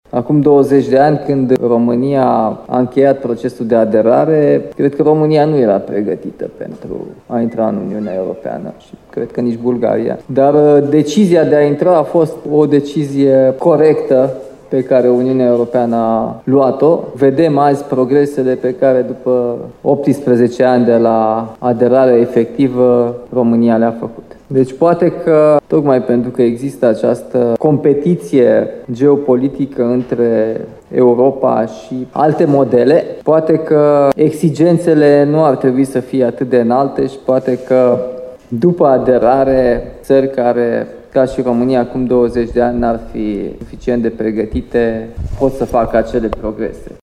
Cerințele pentru statele care doresc să intre în Uniunea Europeană ar trebui relaxate, a declarat președintele României, Nicușor Dan, în deschiderea summitului dedicat extinderii Uniunii Europene, de la Timișoara.